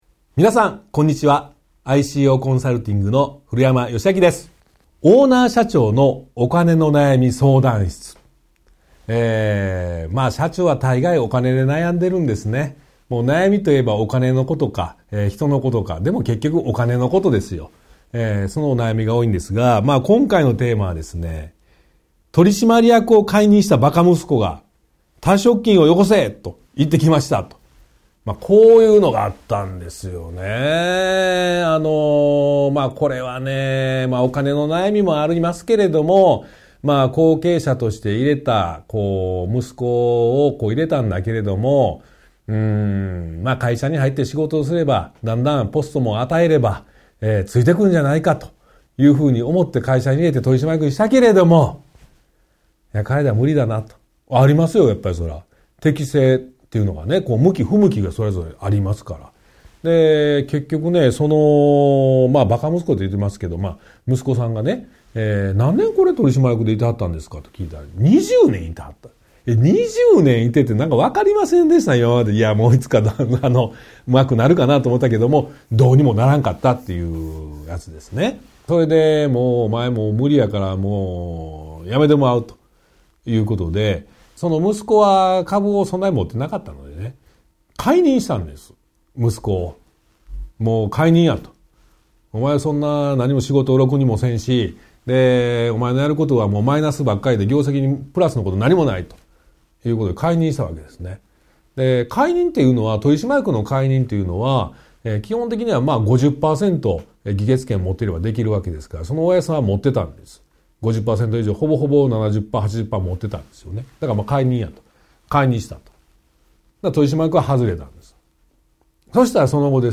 ワンポイント音声講座 相談2：取締役を解任したバカ息子が、退職金をよこせ！